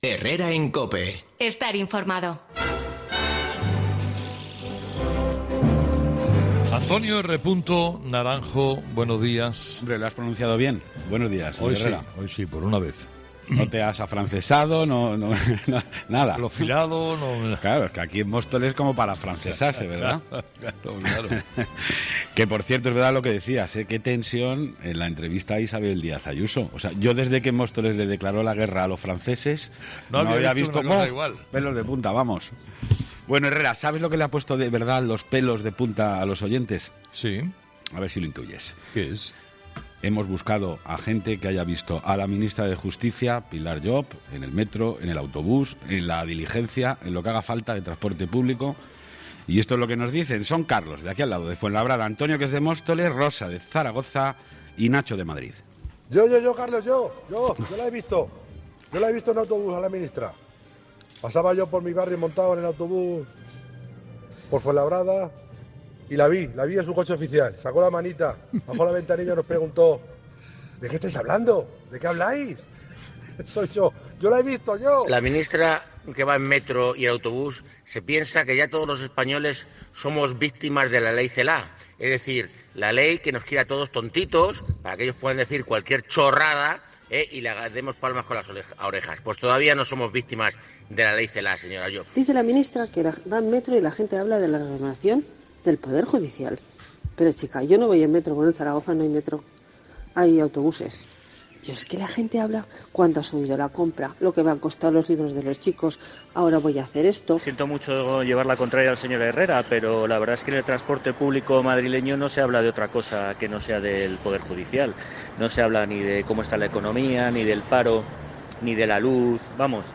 Los oyentes, de nuevo, protagonistas en 'Herrera en COPE' con su particular tertulia.